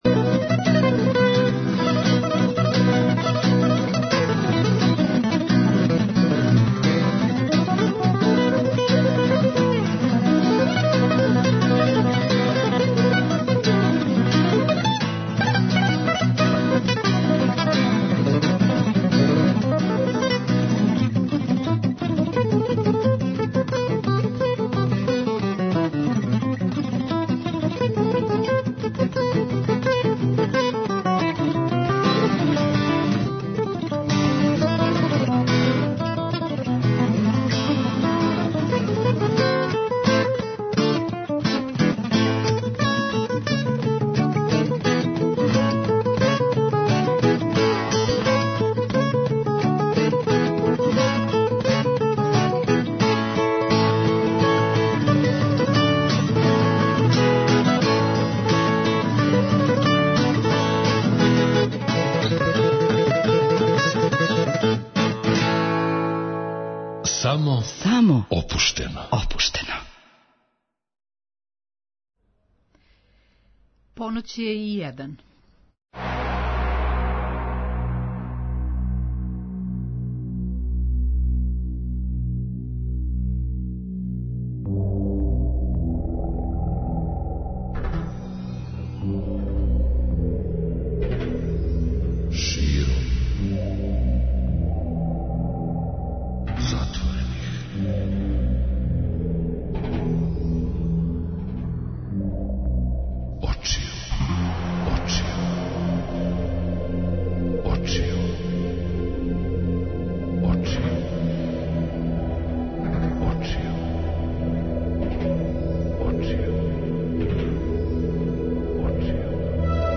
Гост: Снежана Мишковић, позната и као Викторија